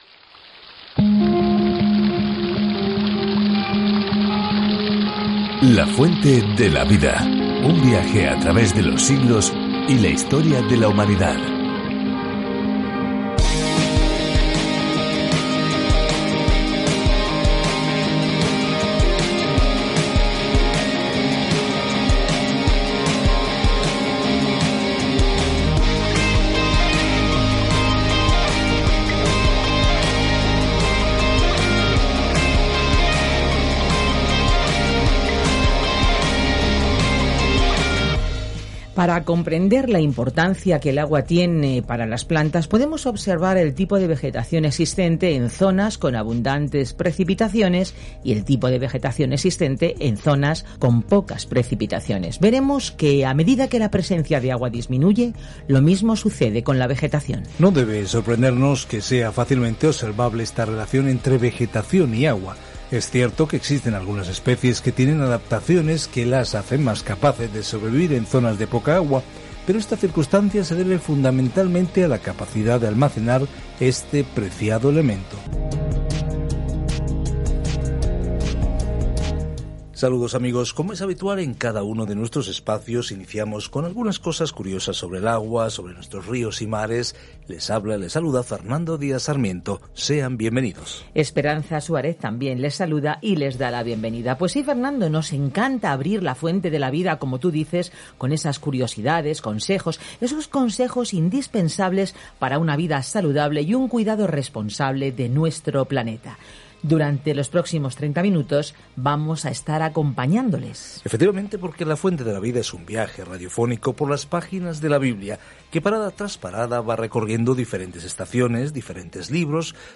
Scripture 2 John 1:6-8 Day 3 Start this Plan Day 5 About this Plan Esta segunda carta de John ayuda a una mujer generosa y a una iglesia local a saber cómo expresar el amor dentro de los límites de la verdad. Viaja diariamente a través de 2 Juan mientras escuchas el estudio en audio y lees versículos seleccionados de la palabra de Dios.